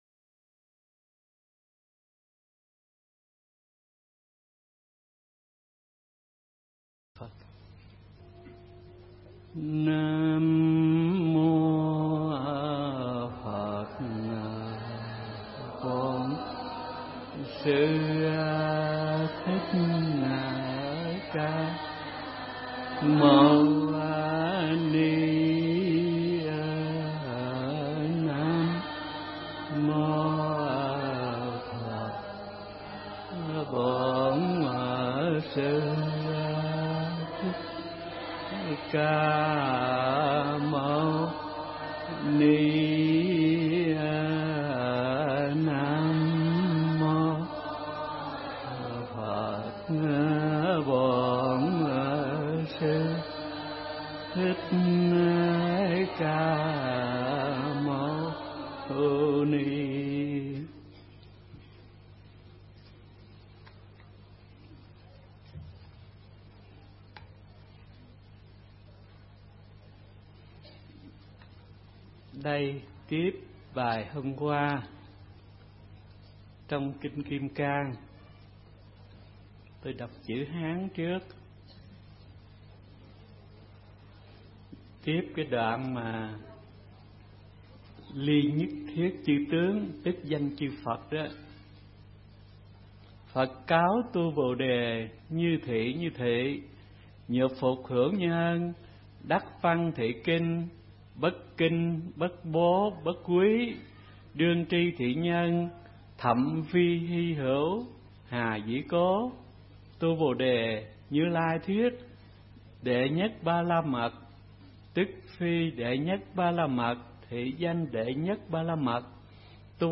Bài Giảng mp3 Thuyết Pháp Kinh Kim Cang Giảng Giải Phần 5 – Hòa Thượng Thích Thanh Từ giảng tại Thiền Viện Trúc Lâm (Đà Lạt), năm 2002